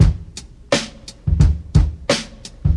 Free drum groove - kick tuned to the C# note. Loudest frequency: 845Hz
86-bpm-breakbeat-c-sharp-key-0lr.wav